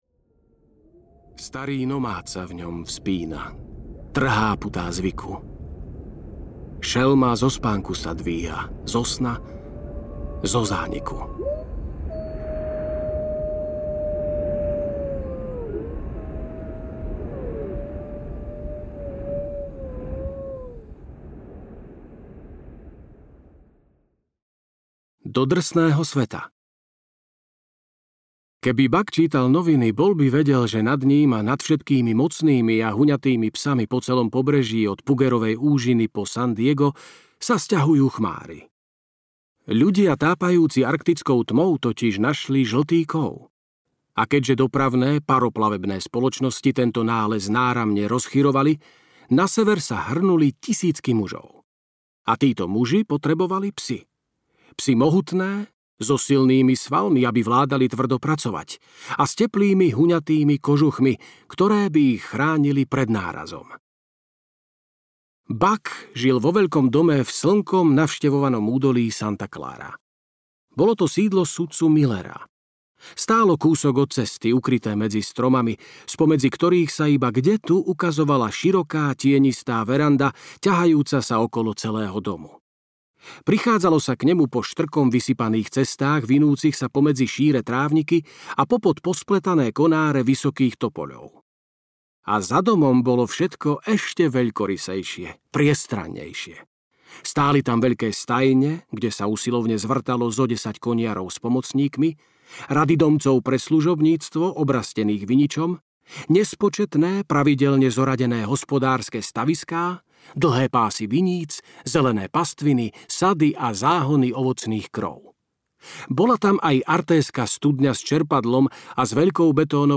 Volanie divočiny audiokniha
Ukázka z knihy